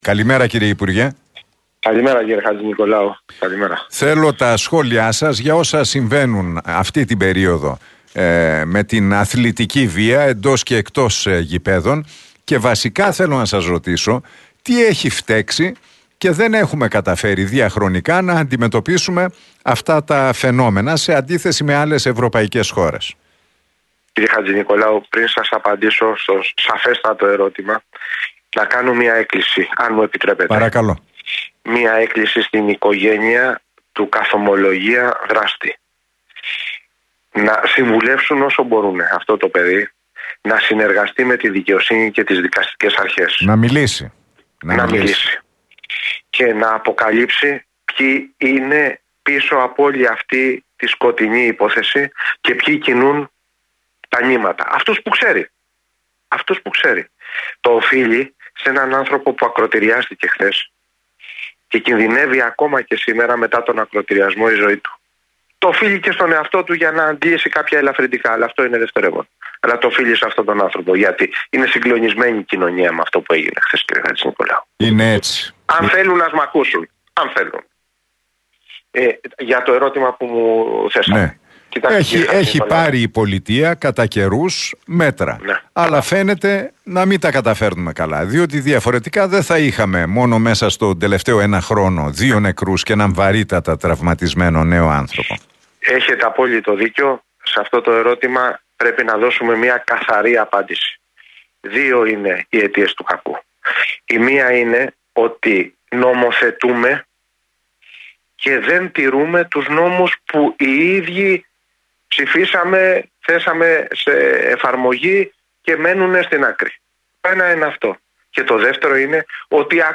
Ο Σταύρος Κοντονής απηύθυνε έκκληση μέσω της εκπομπής του Νίκου Χατζηνικολάου στον Realfm 97,8 στην οικογένεια του καθ’ ομολογίαν δράστη για την επίθεση σε βάρος του 31χρονου αστυνομικού.